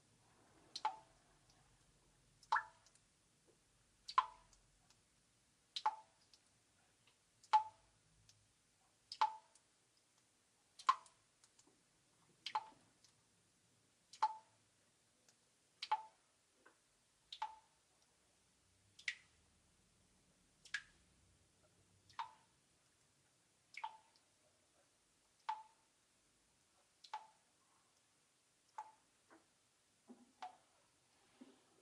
Звук мокрых падающих капель, пропитанных горечью